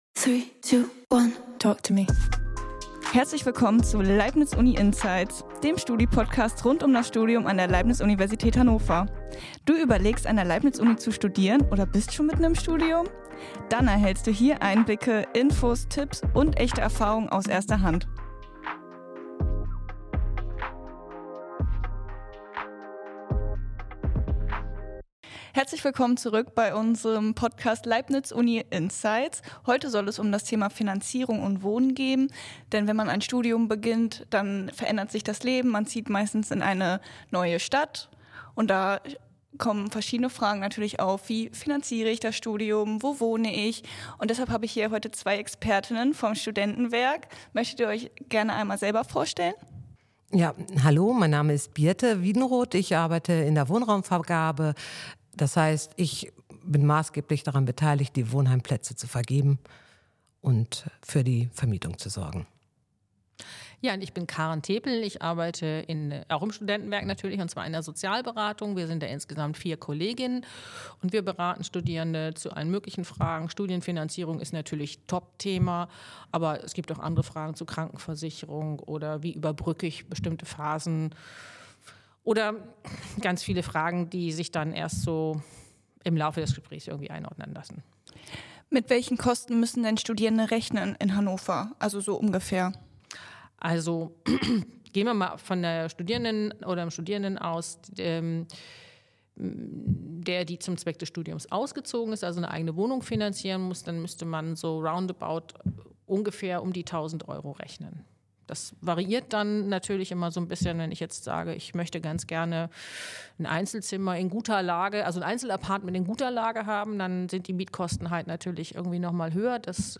Im Gespräch geht es unter anderem um typische monatliche Ausgaben, verschiedene Finanzierungsmöglichkeiten wie BAföG und Nebenjobs sowie um wichtige Hinweise zur Antragstellung. Außerdem geben die Expertinnen Einblicke in das Wohnangebot des Studentenwerks, erklären, worauf bei der Wohnungssuche zu achten ist, und warnen vor typischen Betrugsmaschen auf dem Wohnungsmarkt.